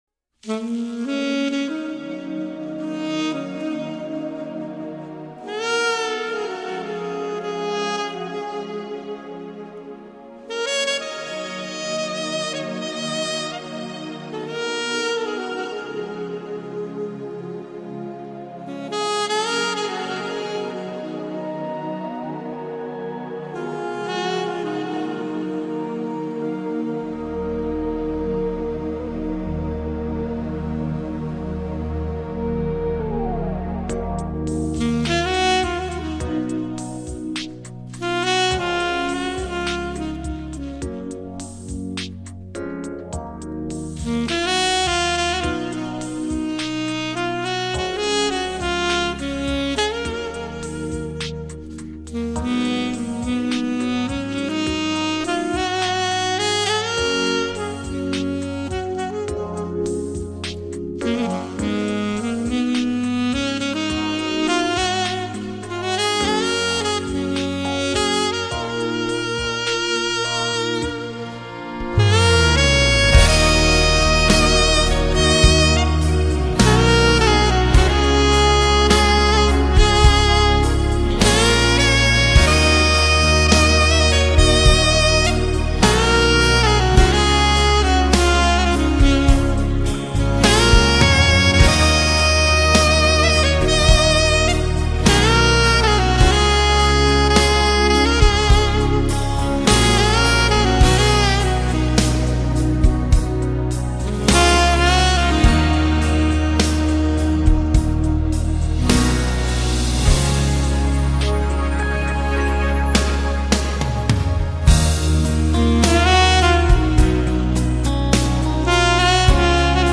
Saksofon_i_gitara___Bozhestvennaya_muzyka___melodiya_nochi____mp3davalka_.mp3